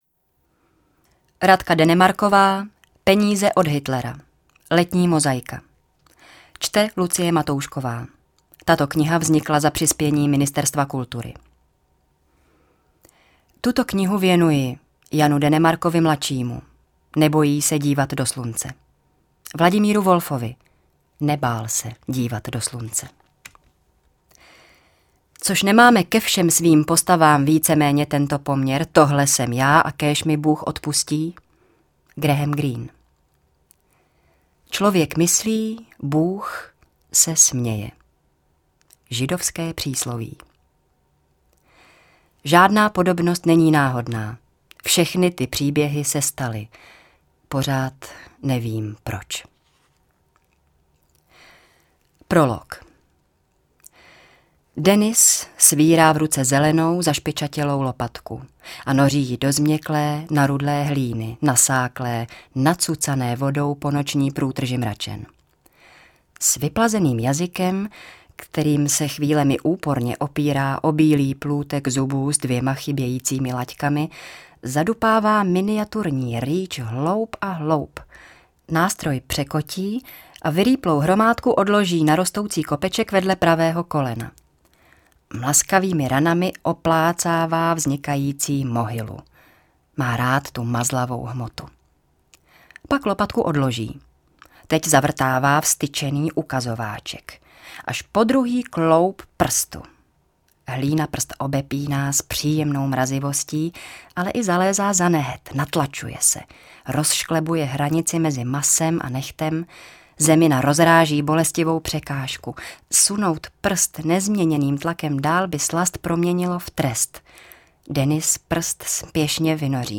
Čte: